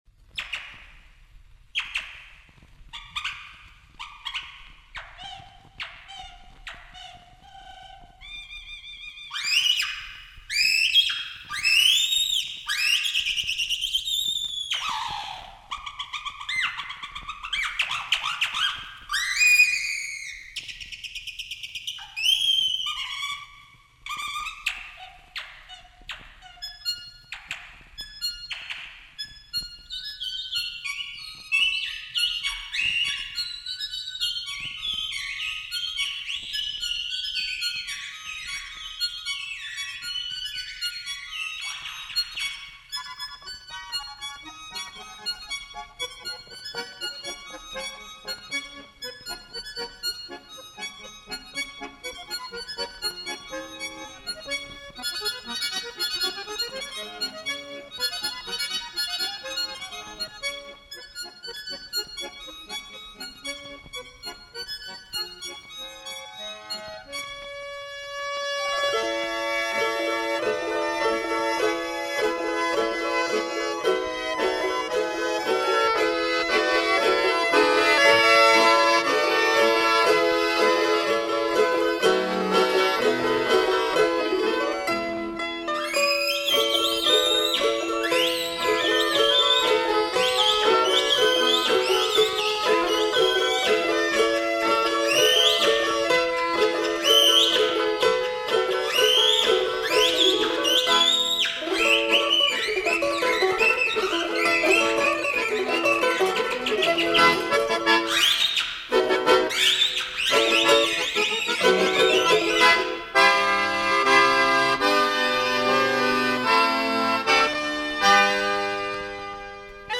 народные мелодии Исполняет
береста, гармоника, ложки, трещотки
звончатые гусли, жалейка
баян